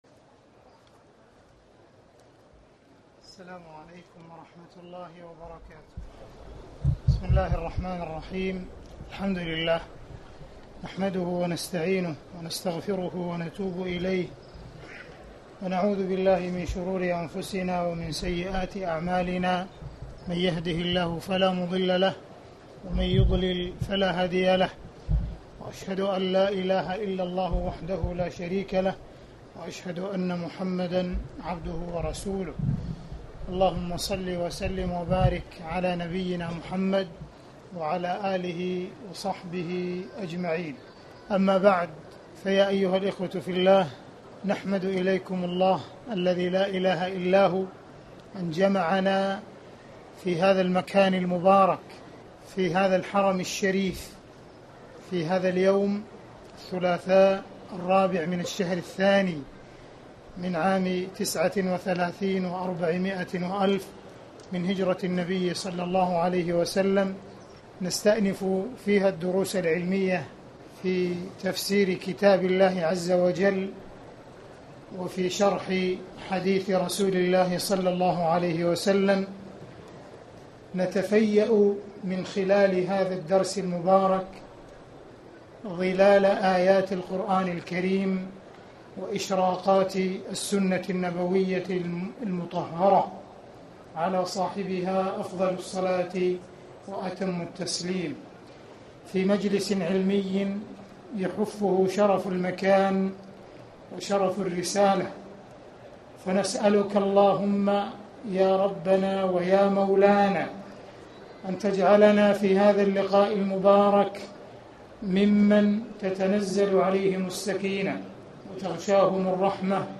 تاريخ النشر ٤ صفر ١٤٣٩ هـ المكان: المسجد الحرام الشيخ: معالي الشيخ أ.د. عبدالرحمن بن عبدالعزيز السديس معالي الشيخ أ.د. عبدالرحمن بن عبدالعزيز السديس سورة الرحمن